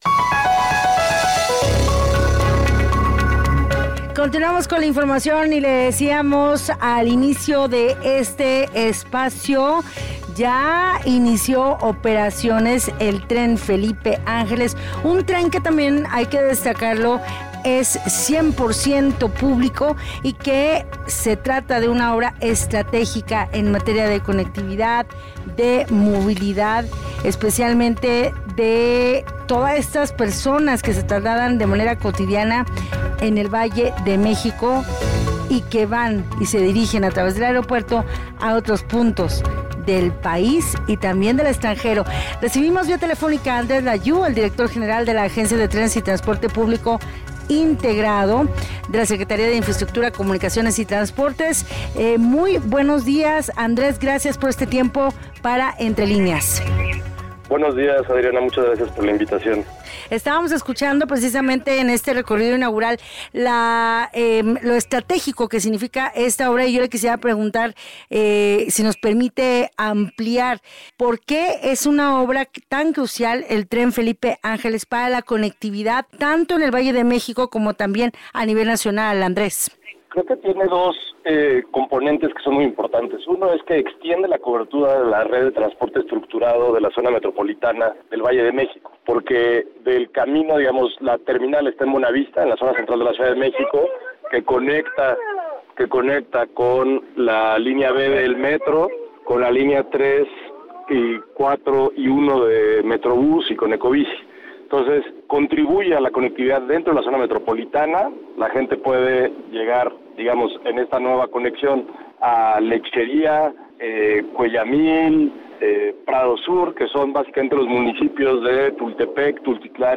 Escucha este conversatorio con el director general de la Agencia de Transporte Ferroviario, Andrés Lajous.